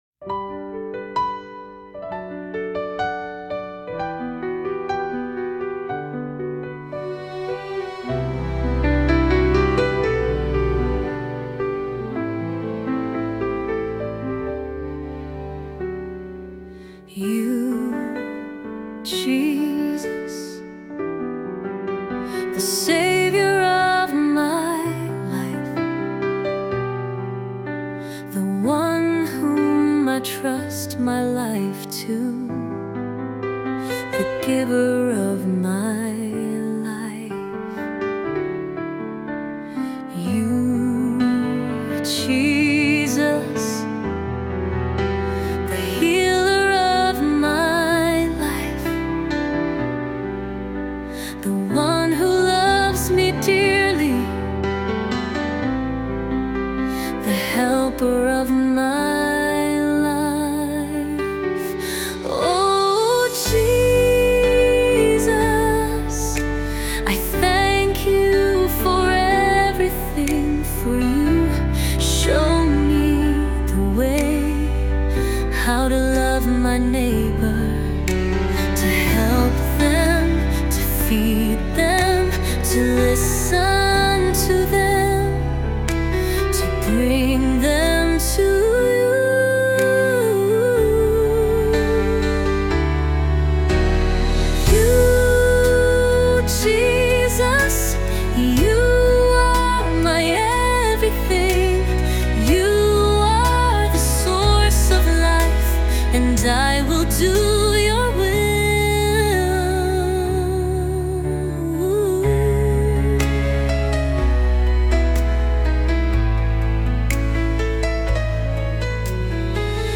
Listen to AI generated music